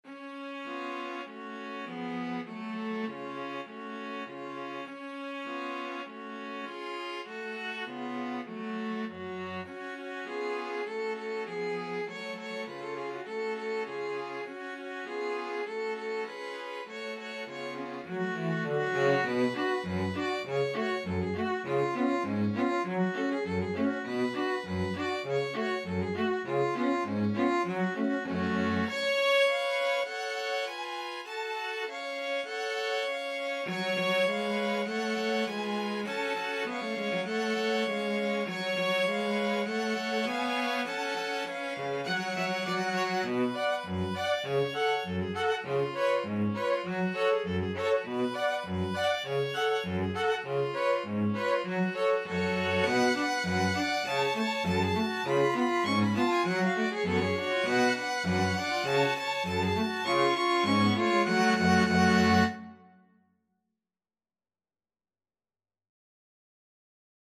Traditional Music of unknown author.
2/4 (View more 2/4 Music)
Moderato
World (View more World String Quartet Music)